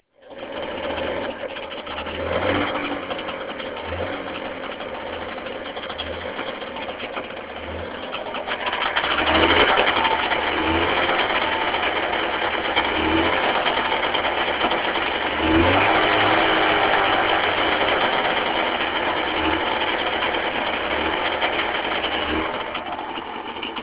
Nüüd on võimalik seda ka autentsemalt kuulata. Kahjuks mu vana Nokia telefon just suuremat helikvaliteeti ei luba, tegelikult too ikka nii plekiselt ei kõla, hoopis mahedam on see hääl.